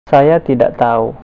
speaker.gif (931 bytes) Click on the word to hear it pronounced.